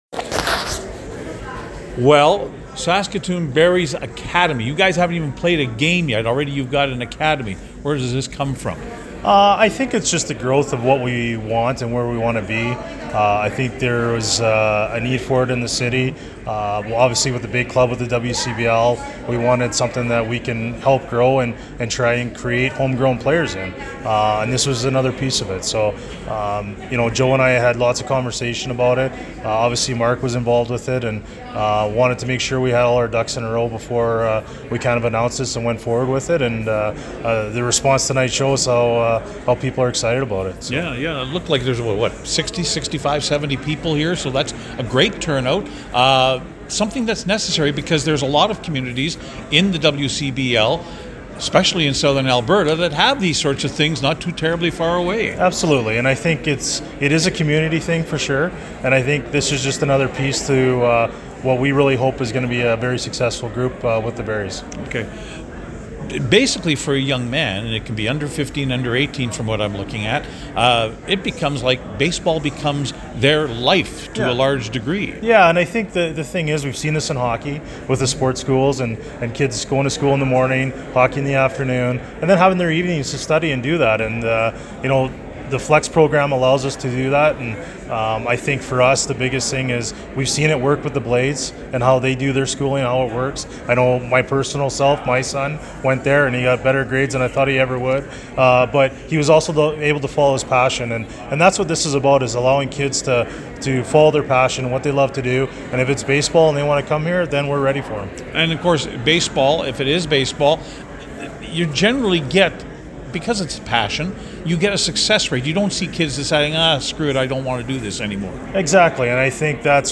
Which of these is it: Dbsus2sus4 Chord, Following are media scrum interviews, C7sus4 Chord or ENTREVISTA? Following are media scrum interviews